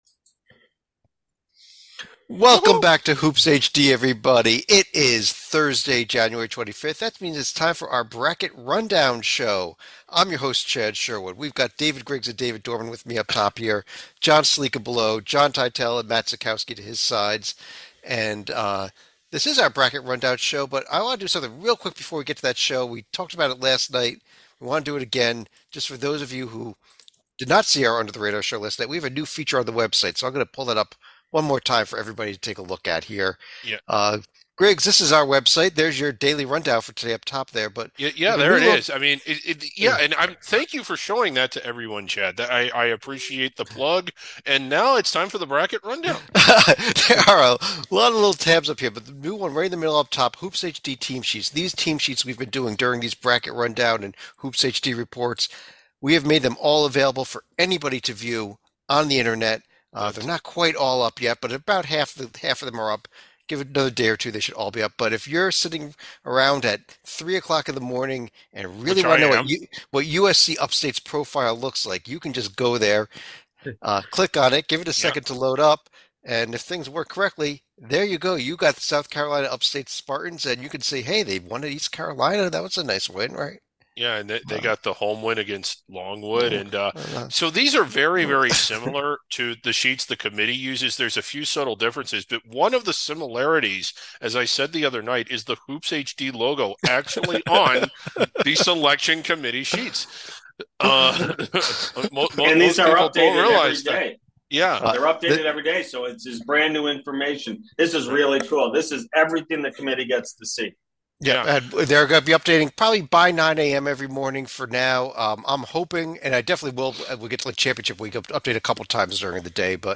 They discuss, assess, and debate each team as they are revealed. See who the #1 seeds are, who the rest of the protected seeds are, who is on the bubble, and who just missed it.